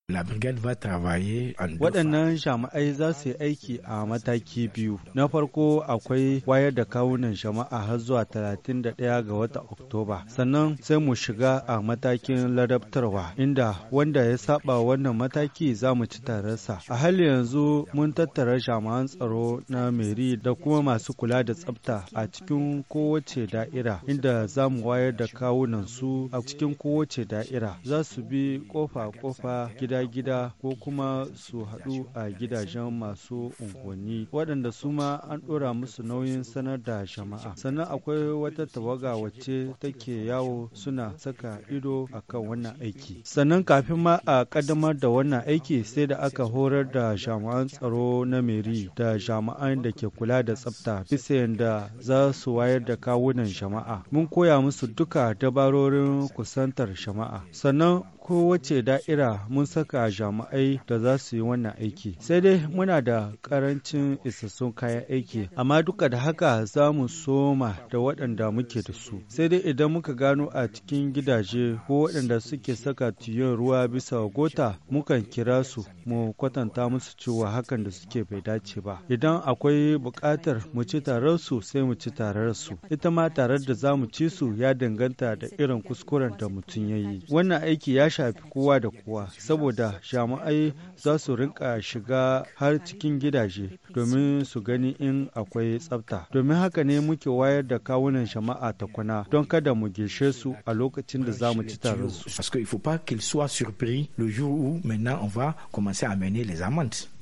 Magazine en haoussa